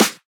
Index of /neuro/Optiv & BTK/Drums - One Shots
Snares (16).wav